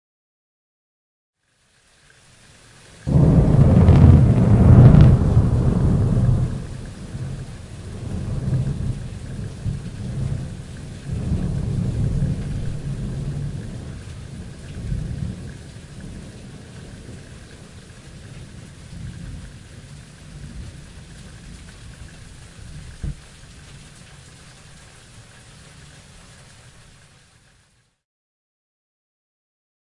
大雷声效 免费高质量的声音效果
描述：大雷声音效免费高品质音效